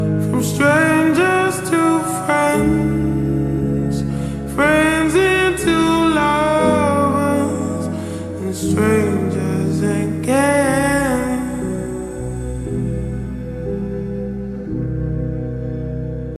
slowed and reverbed version